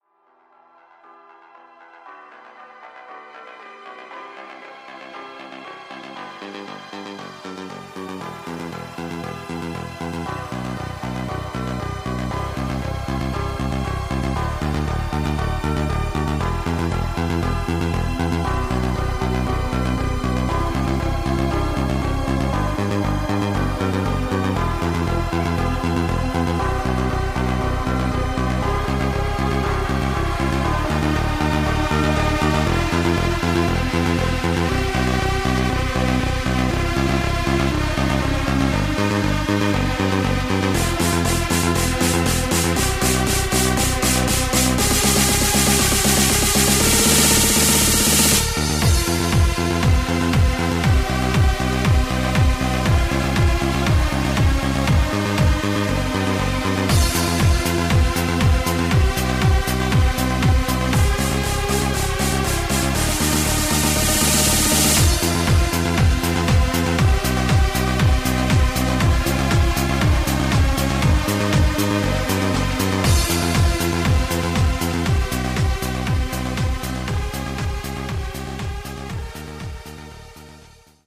:wow: Live @ Motor Show di Bologna 8 dicembre 2008...